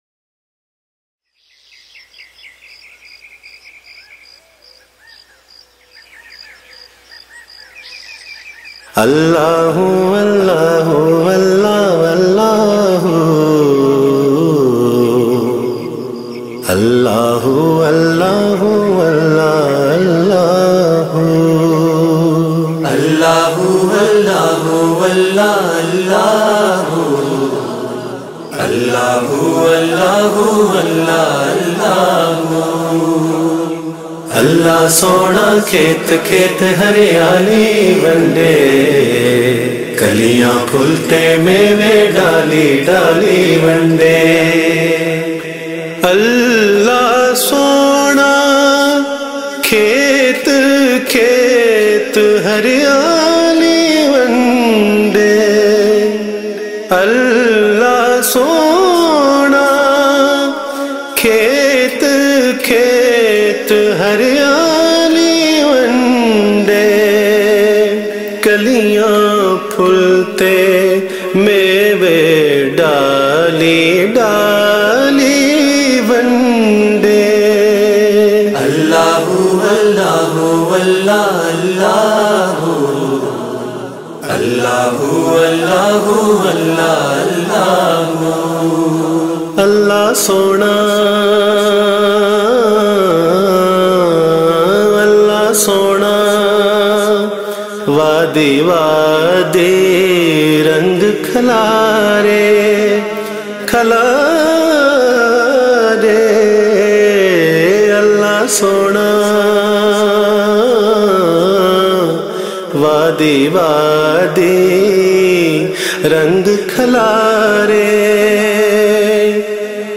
Naat MP3